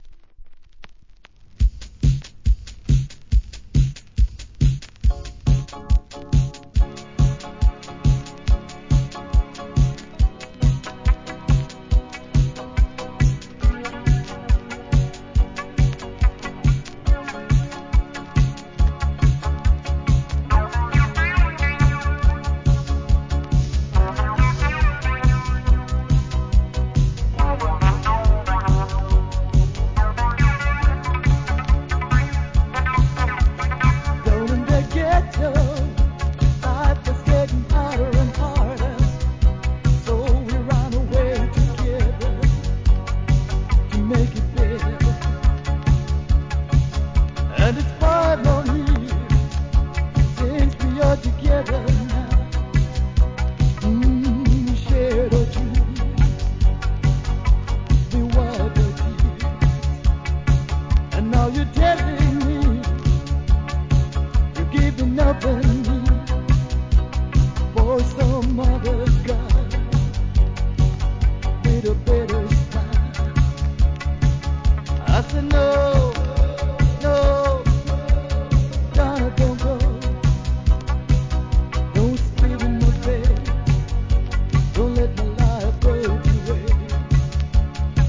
店舗 数量 カートに入れる お気に入りに追加 1987年、JAMAICAN DISCO!